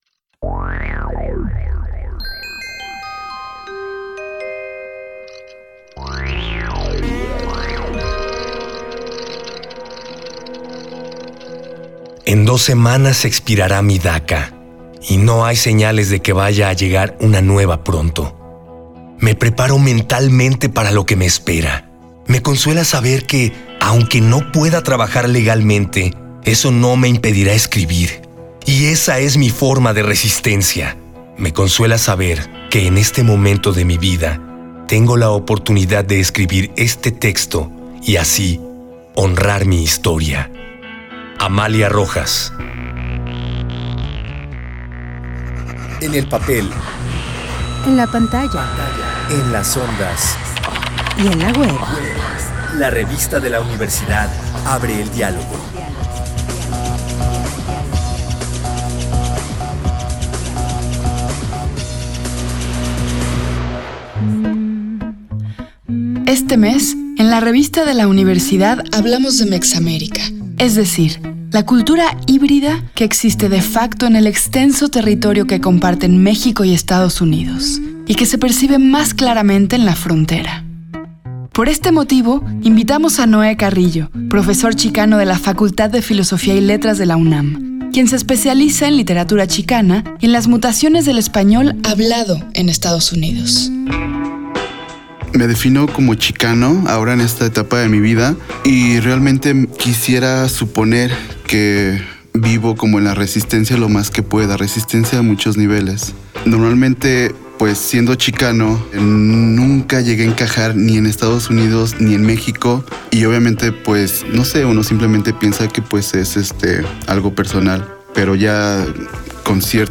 Fue transmitido el jueves 3 de mayo de 2018 por el 96.1 FM.